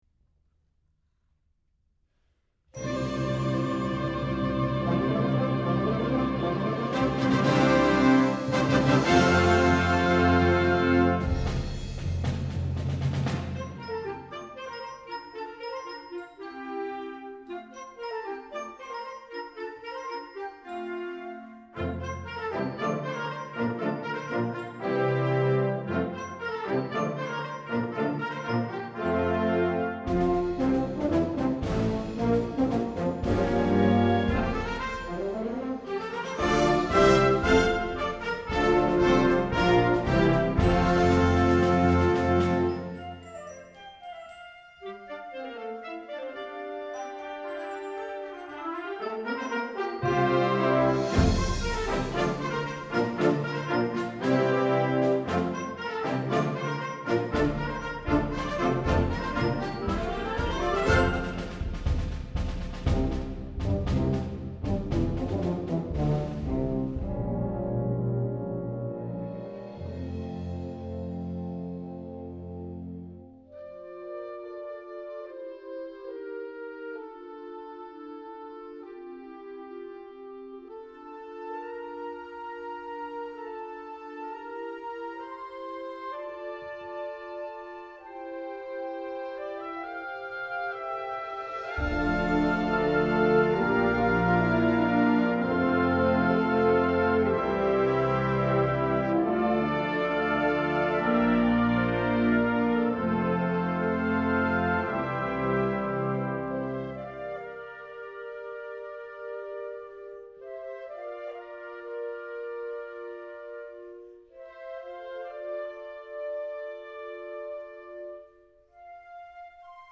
Genre: Band
Flute
Alto Saxophone
Tuba
Timpani
Percussion 2 (bass drum, suspended cymbal)